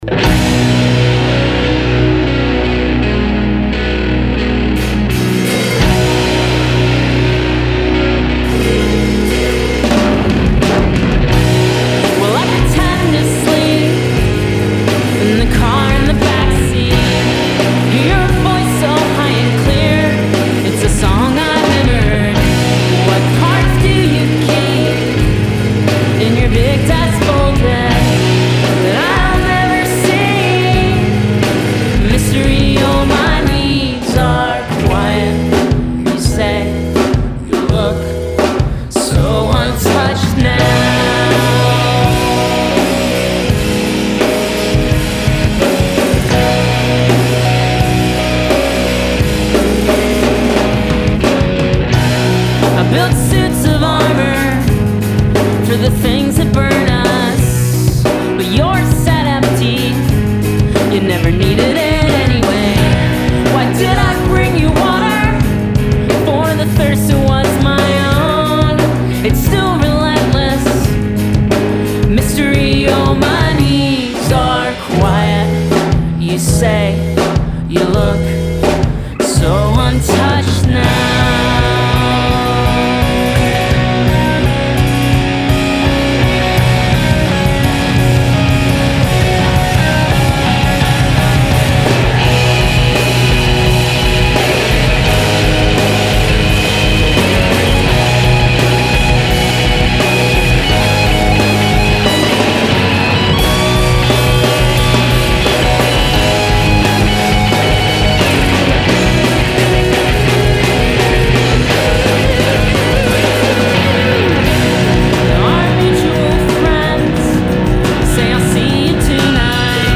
queer-positive pop-punk quintet
guitar
bass
drums
rhodes piano